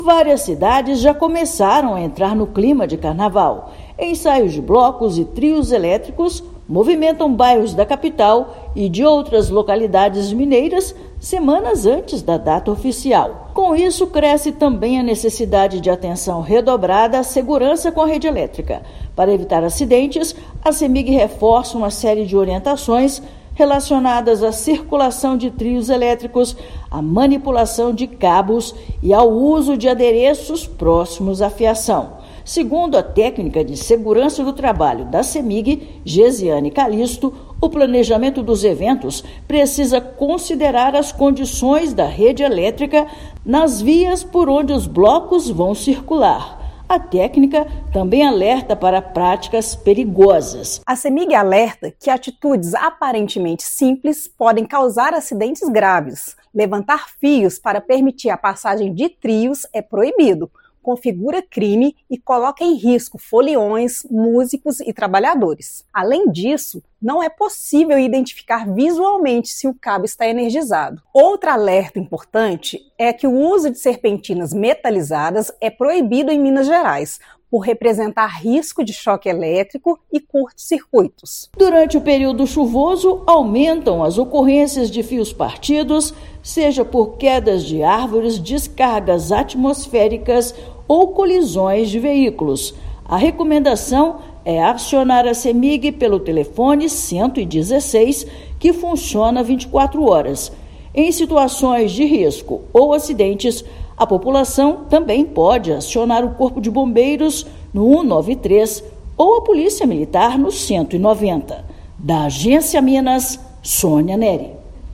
[RÁDIO] Cemig destaca os principais cuidados com a rede elétrica durante ensaios e desfiles de blocos de Carnaval
Ensaios e eventos de rua ampliam riscos e exigem planejamento e atenção redobrados. Ouça matéria de rádio.